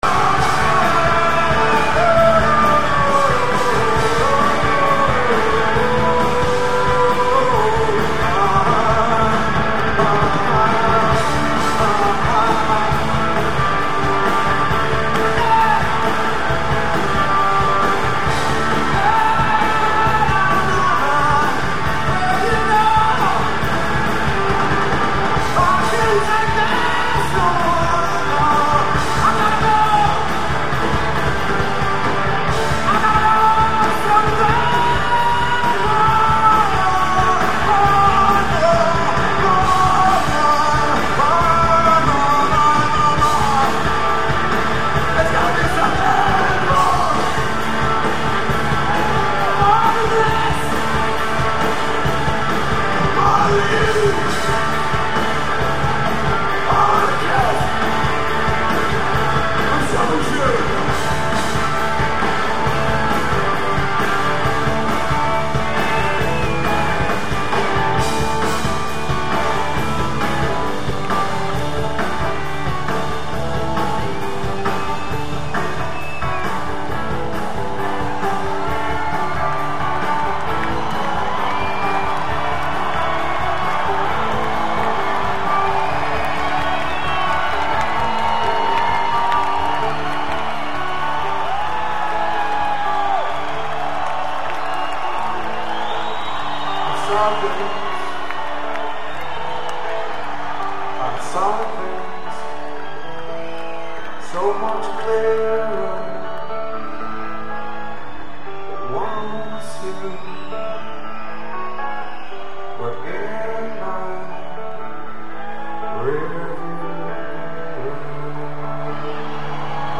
06/27/98 - Alpine Valley: East Troy, WI [115m]